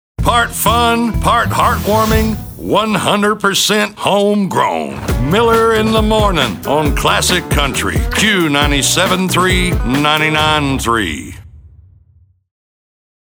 Sweeper – Morning show